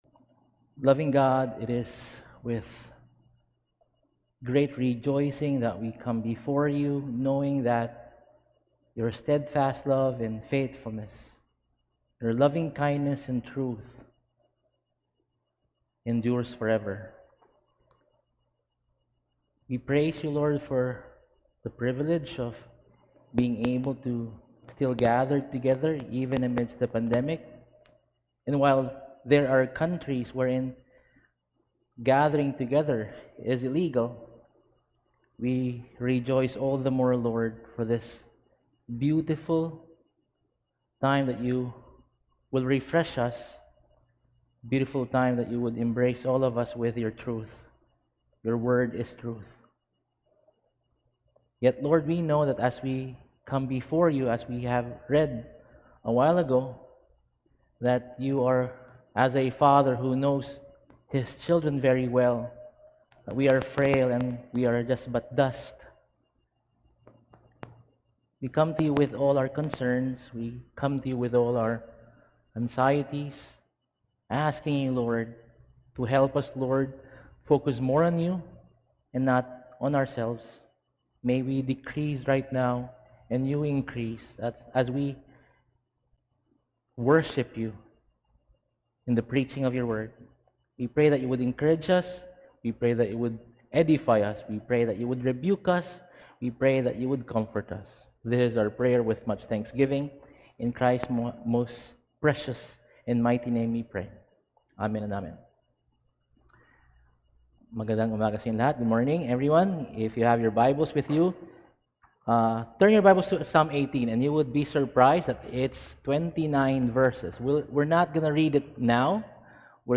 Service: Sunday